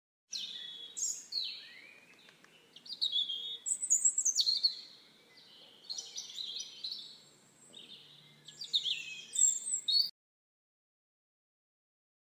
Sound Effects
Bird Noises